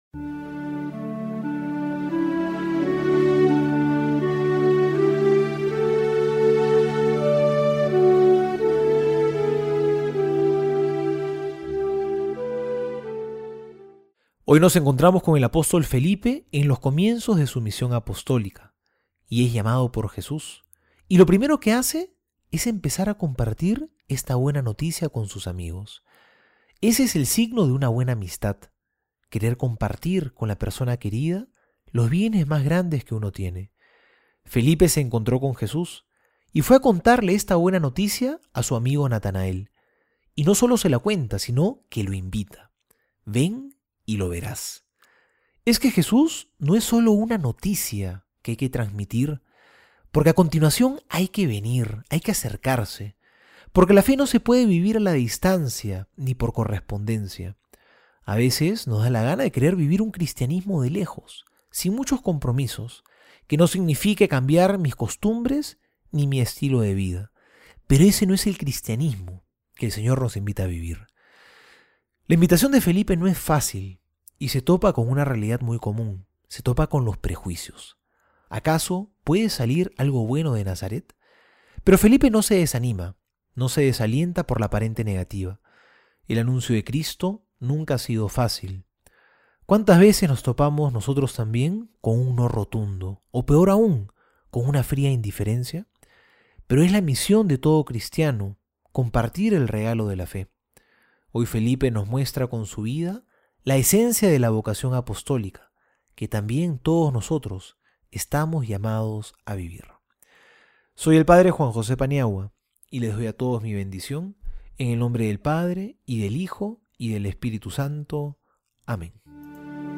Homilía para hoy:
Homilia Enero 05.mp3